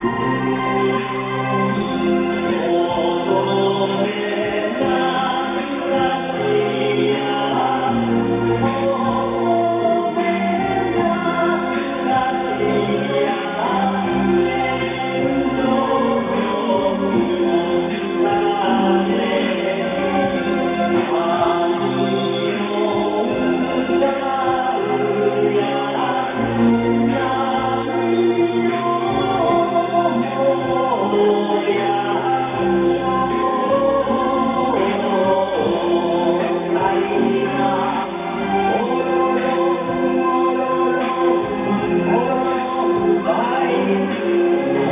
Ending Theme Lullaby of Japanese trad. with strings